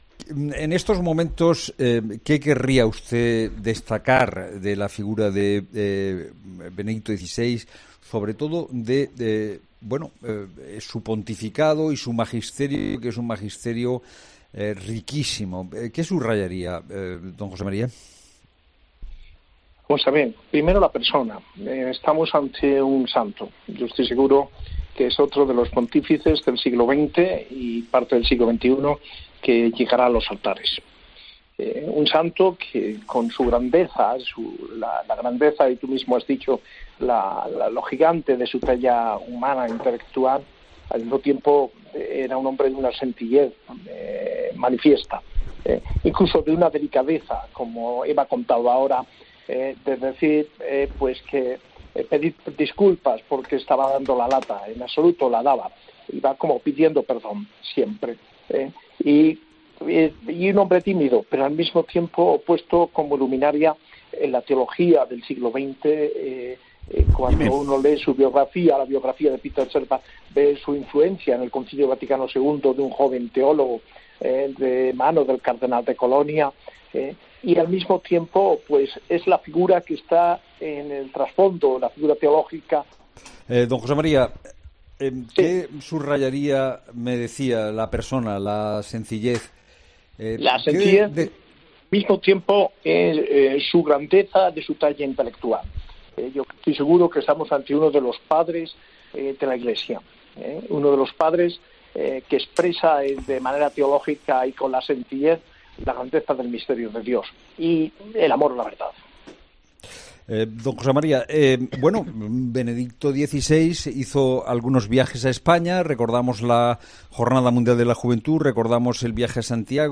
El arzobispo coadjutor de Granada ha querido recordar la figura de Joseph Ratzinger en el programa especial de COPE: "Estamos ante un santo"
José María Gil Tamayo, arzobispo coadjutor de Granada, ha sido uno de los protagonistas al principio del programa especial de la Cadena COPE sobre el fallecimiento del Papa emérito Benedicto XVI.